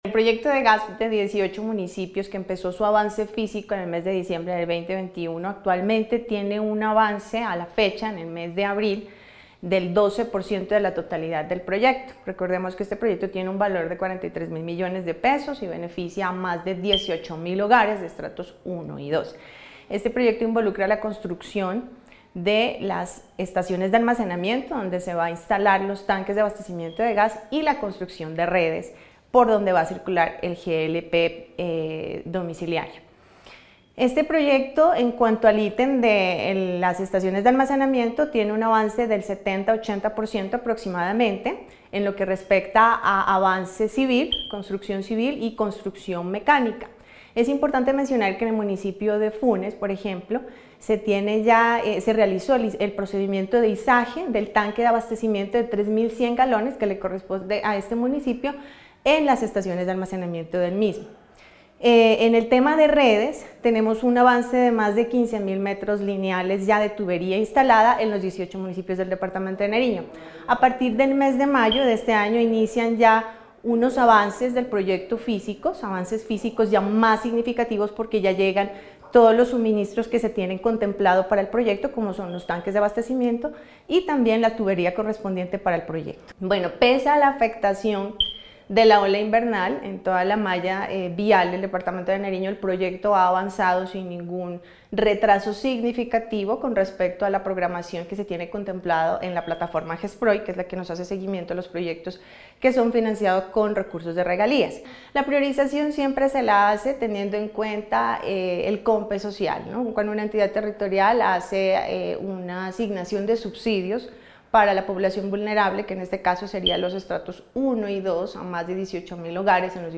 La Subsecretaria de Minas y Energía de Nariño, Johanna Morillo, informó que el avance general de la fase de construcción de redes y sistemas estructuras de almacenamiento, alcanza un 12% y se encuentra dentro de los tiempos establecidos en el contrato.